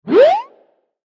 Cartoon Slide Whistle Zip Whoosh Sound Effect
Description: Cartoon slide whistle zip whoosh sound effect. A classic high-pitched cartoon sound effect featuring a fast upward pitch sweep.
This iconic “fiju” whistle adds nostalgic, whimsical energy to videos, games, apps, and creative projects.
Cartoon-slide-whistle-zip-whoosh-sound-effect.mp3